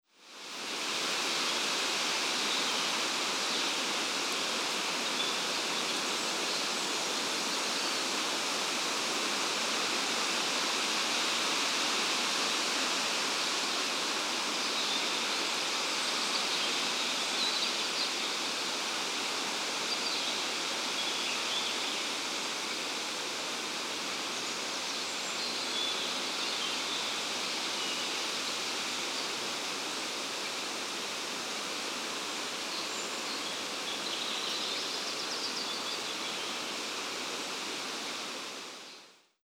Wind Blowing Gently Through the Trees
Mountain-Audio-Wind-Through-Trees.mp3